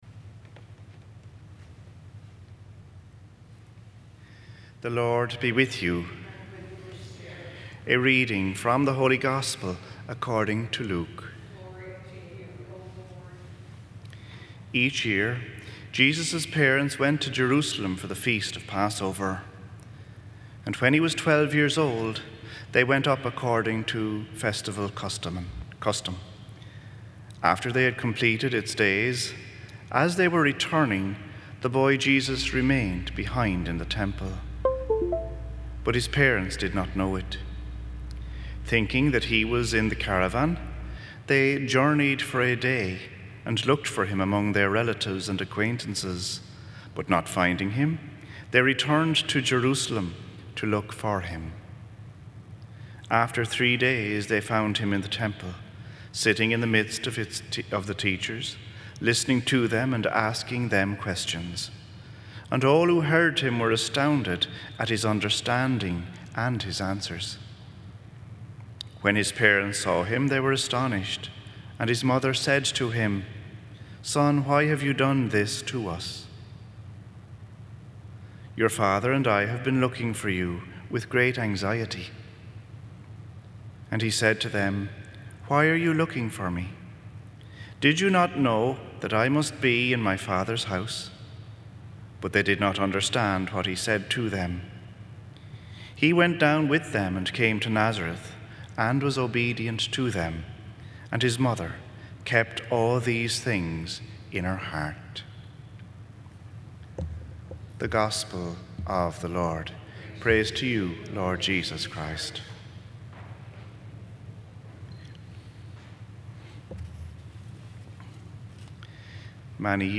Gospel and Homily Podcasts
Holy Family Church Solemnity of the Body and Blood of Christ, June 14, 2020, 5:00 Vigil Mass Play Episode Pause Episode Mute/Unmute Episode Rewind 10 Seconds 1x Fast Forward 10 seconds 00:00 / 17:24 Subscribe Share